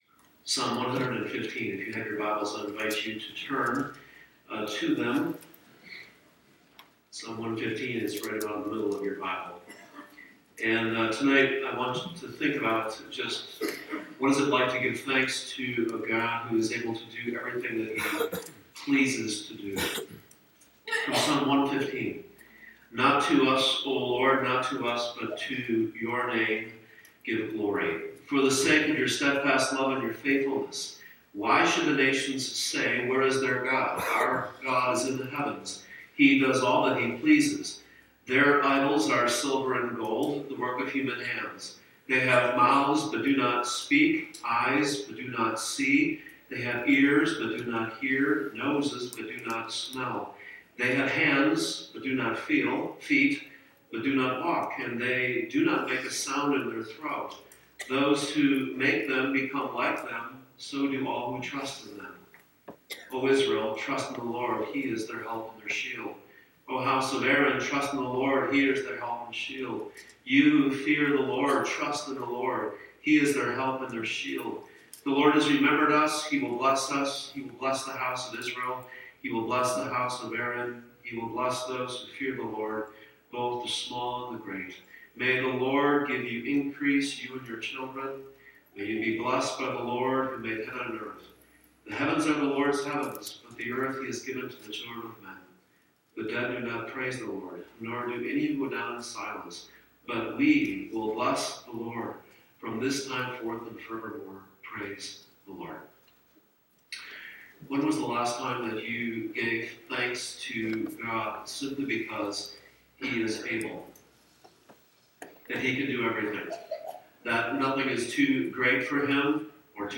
2-24-19PMPineHavensermon.mp3